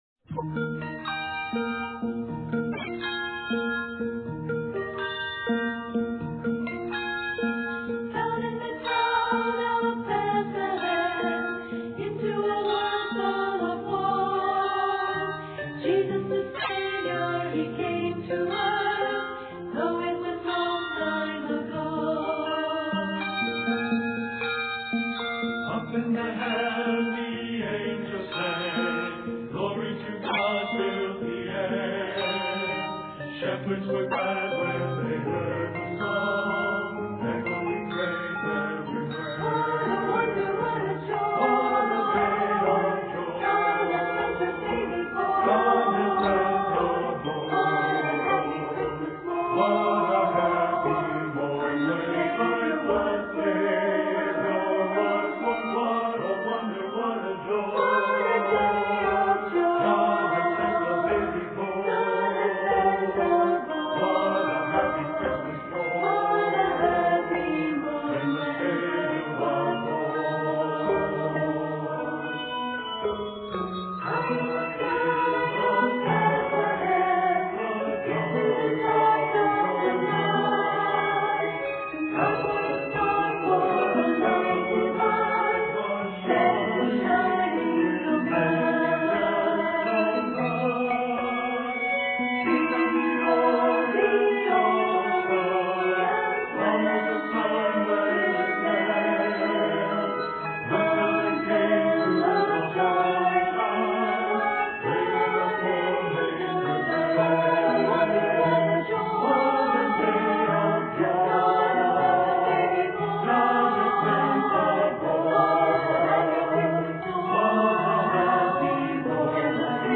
an SATB and SAB setting
3 to 6-octave handbell accompaniment